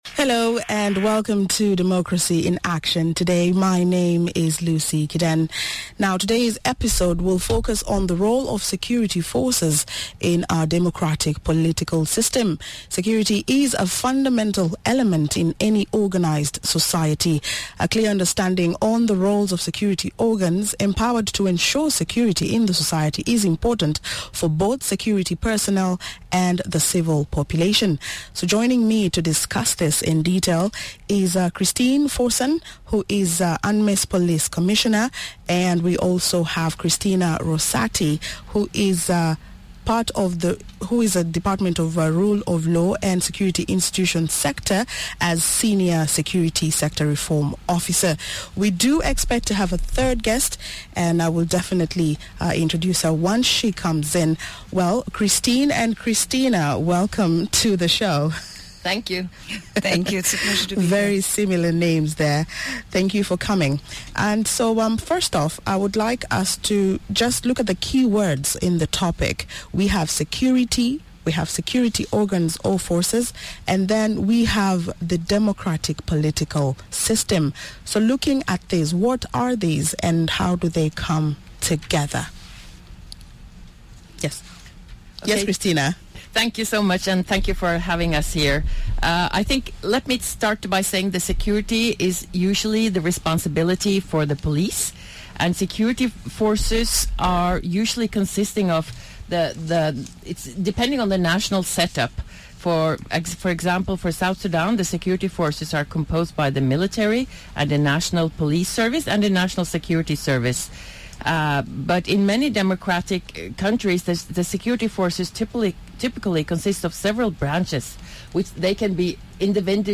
Christine Fossen - UNMISS Police Commissioner